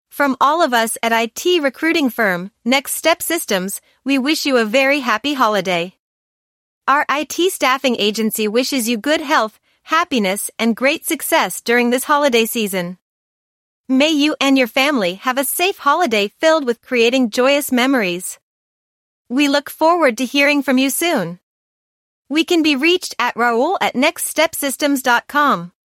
A Happy Holidays Message from IT Recruiting Firm, Next Step Systems Using Artificial Intelligence (AI)
Please take a moment to listen to a Happy Holiday audio message from our IT recruiting firm, Next Step Systems generated by Artificial Intelligence (AI). Our IT staffing agency wishes you good health, happiness and success during this holiday season.